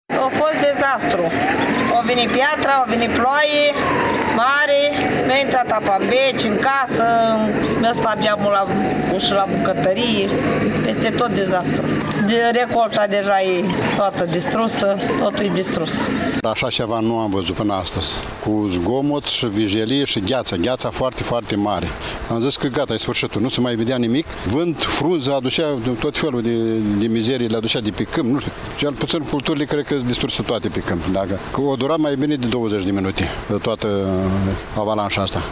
5-iunie-ora-7-Vox-Vaslui.mp3